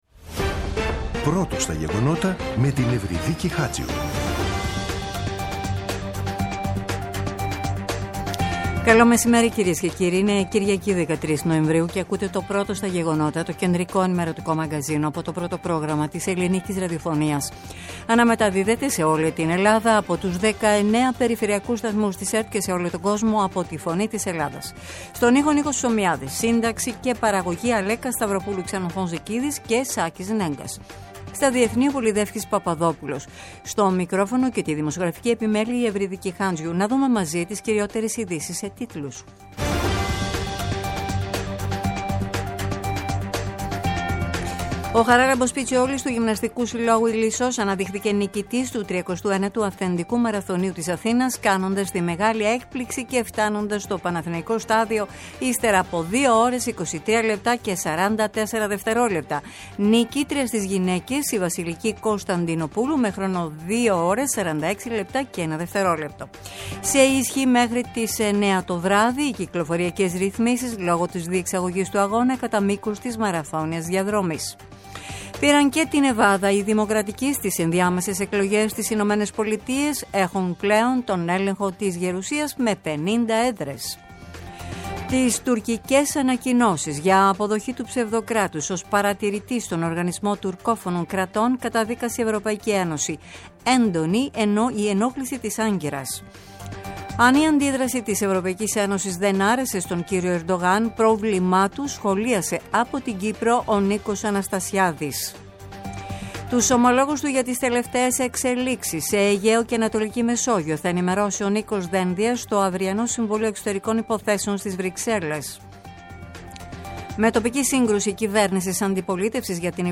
“Πρώτο στα γεγονότα”. Το κεντρικό ενημερωτικό μαγκαζίνο του Α΄ Προγράμματος στις 14.00. Με το μεγαλύτερο δίκτυο ανταποκριτών σε όλη τη χώρα, αναλυτικά ρεπορτάζ και συνεντεύξεις επικαιρότητας.